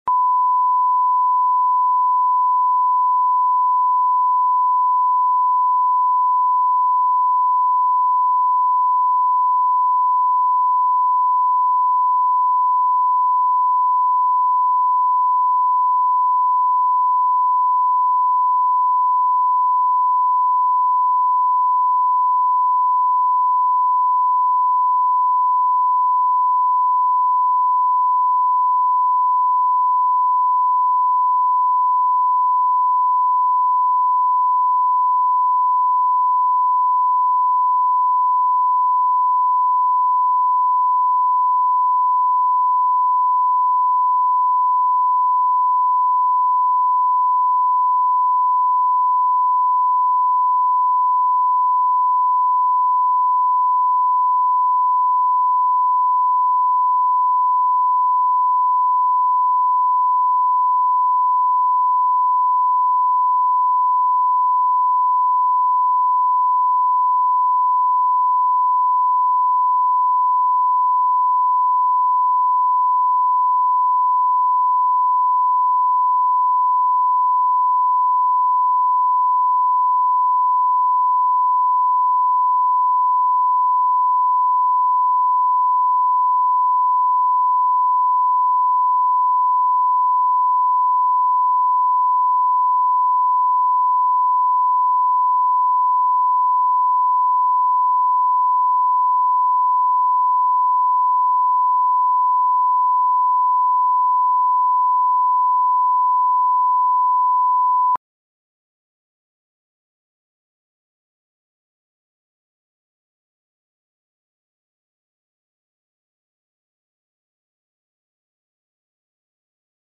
Аудиокнига Сватьи